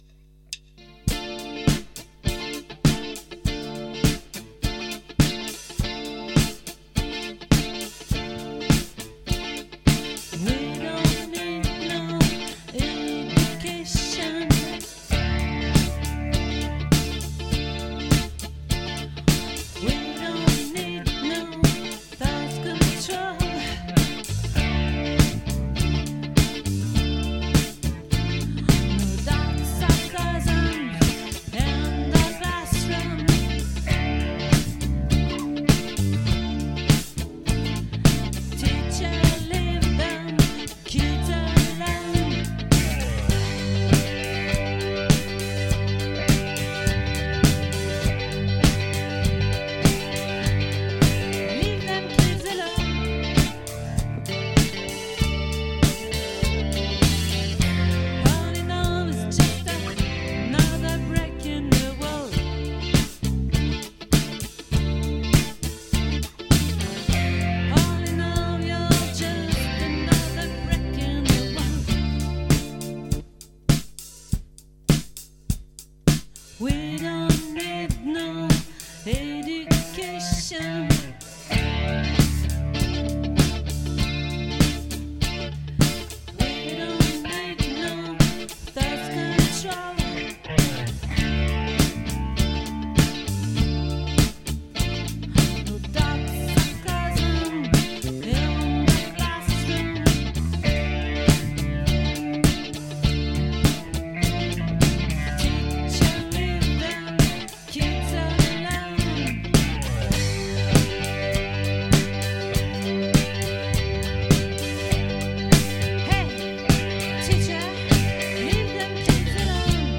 🏠 Accueil Repetitions Records_2022_11_16_OLVRE